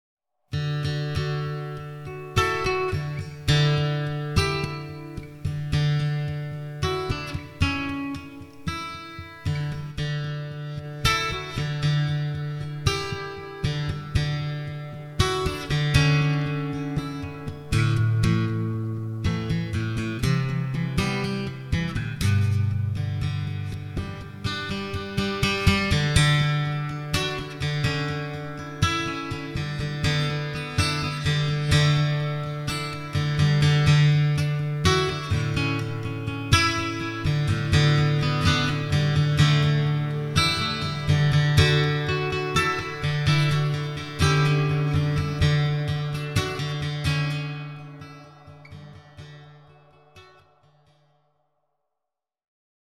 Oder so? your_browser_is_not_able_to_play_this_audio Ich hab jetzt mal absichtlich ein extremen Effekthall plus Delay eingesetzt, um mal ein Gegensatz zu nem Schlafzimmerhall aufzuzeigen...
Die Aufnahme find ich jetzt gar nicht mal so schlecht, nur das Rauschen?